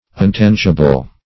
Untangible \Un*tan"gi*ble\, a.